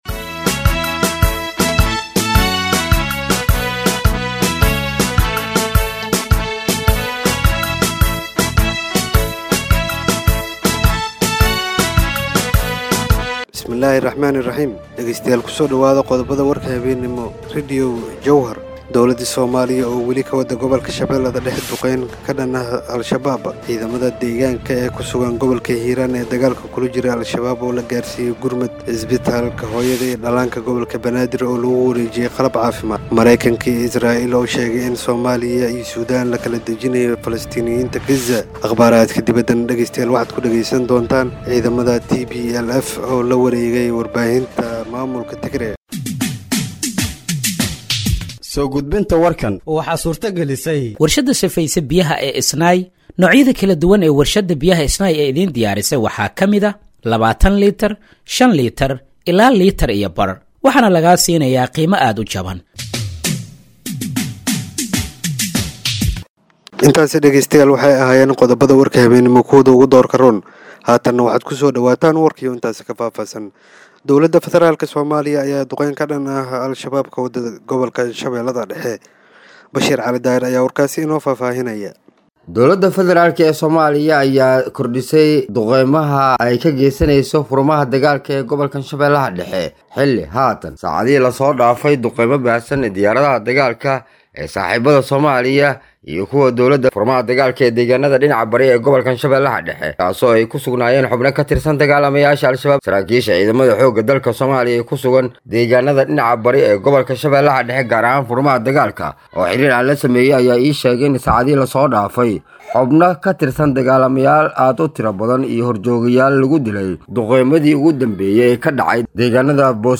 Dhageeyso Warka Habeenimo ee Radiojowhar 14/03/2025
Halkaan Hoose ka Dhageeyso Warka Habeenimo ee Radiojowhar